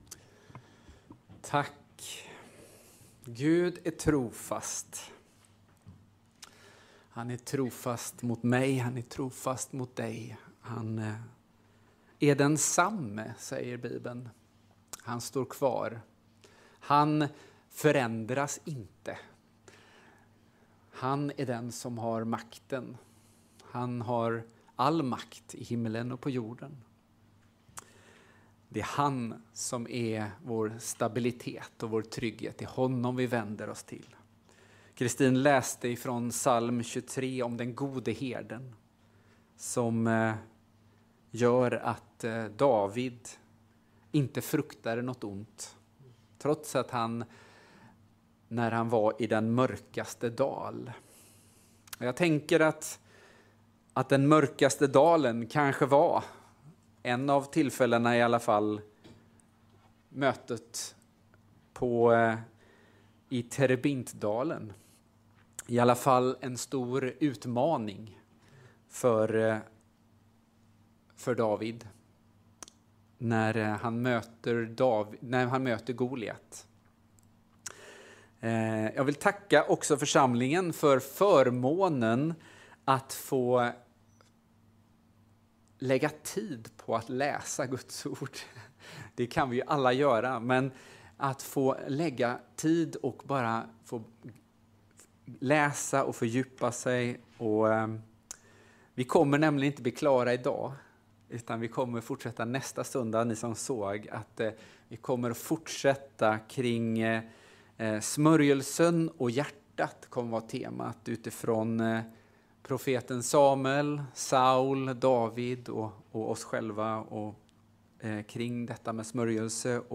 15 februari 10:30 Gudstjänst
Parentation. Rapport från Trysillägret.